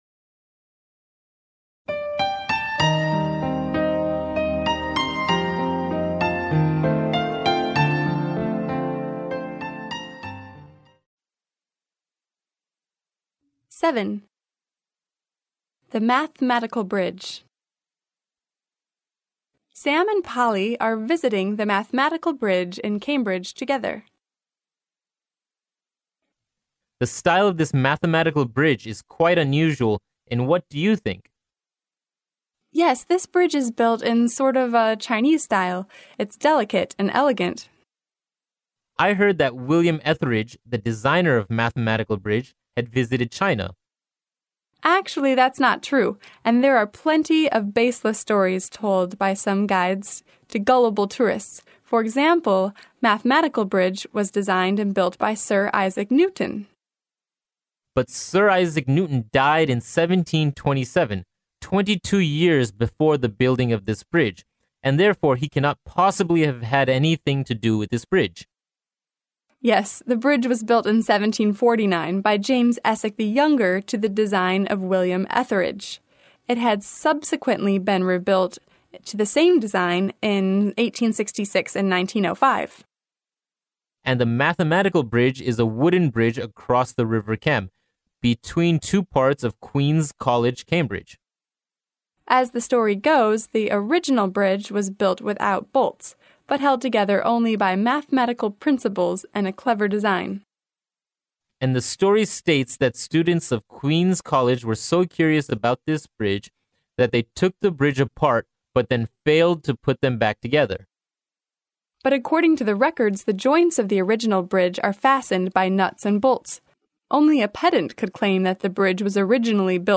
剑桥大学校园英语情景对话07：没有铆钉的数学桥（mp3+中英）